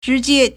直接 zhíjiē
zhi2jie1.mp3